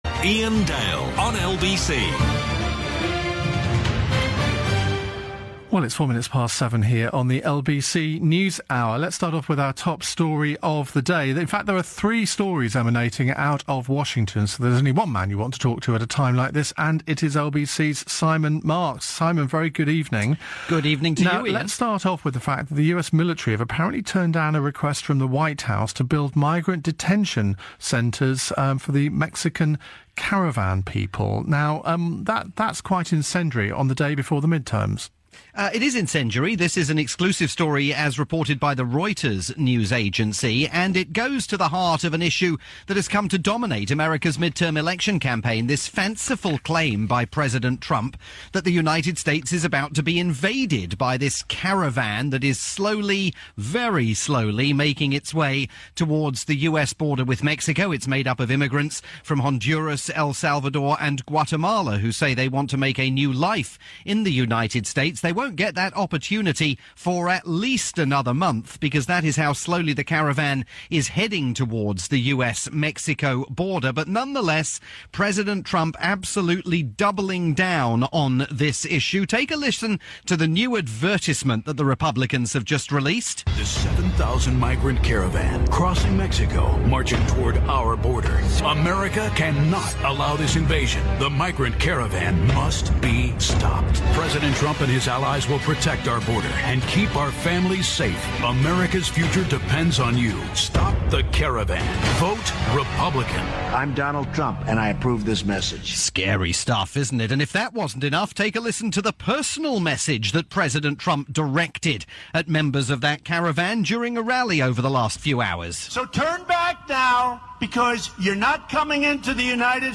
report for Iain Dale's nightly programme on the UK's LBC.